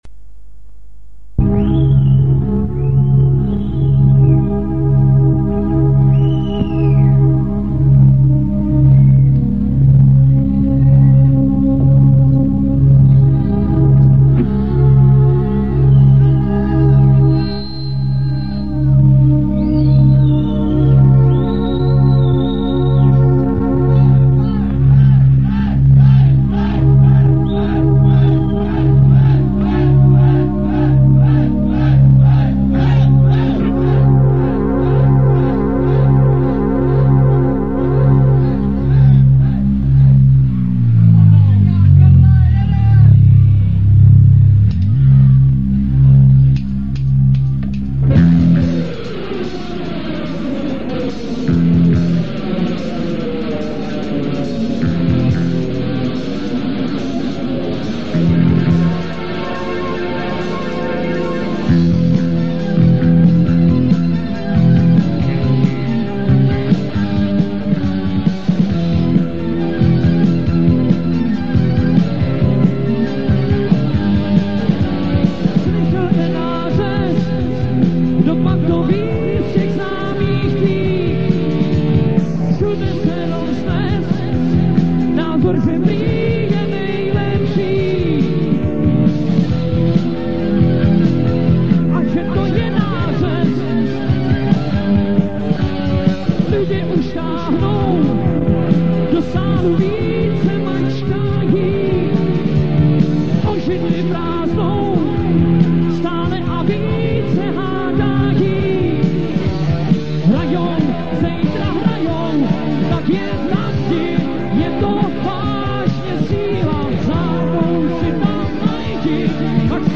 el.kytara, bas.kytara, kl�vesy, bic�.